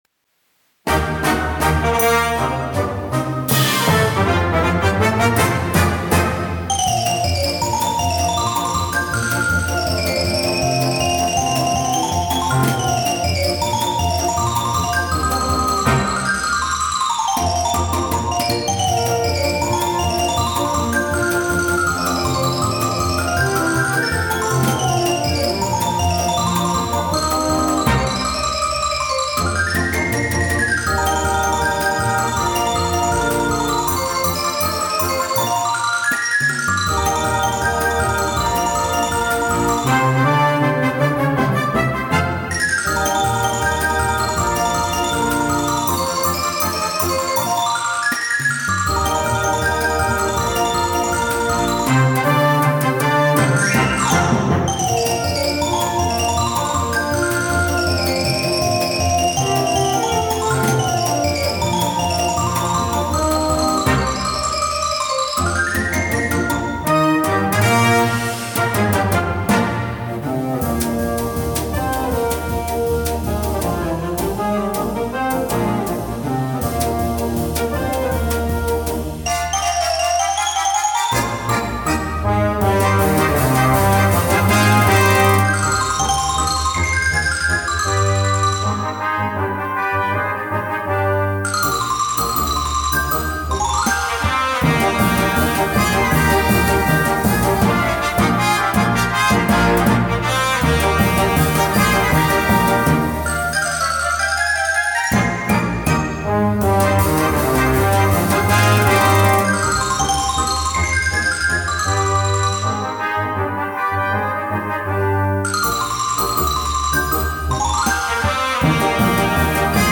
for Xylophone and Concert Band
CategoryXylophone Solo
InstrumentationSolo Xylophone
Timpani
Drum Kit